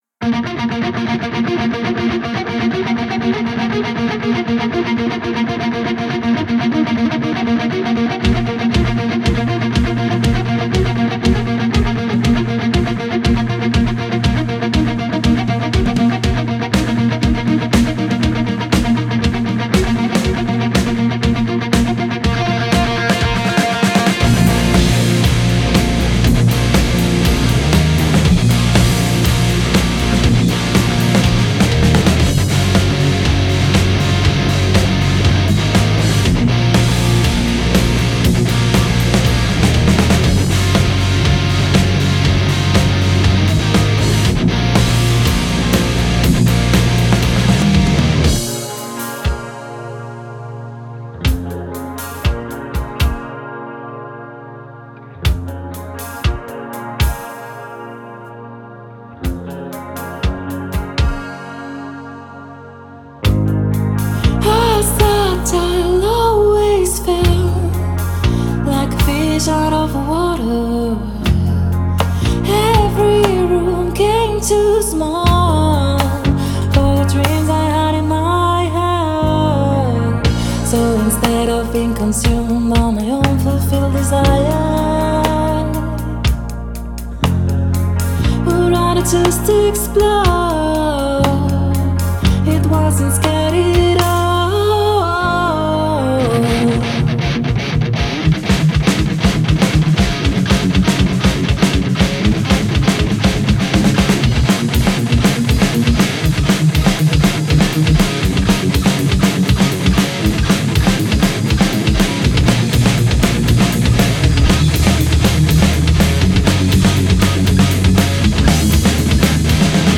an Alternative Metal band from Barcelona
vocals
bass
drums
guitars, backing vocals